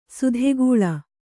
♪ sudhegūḷa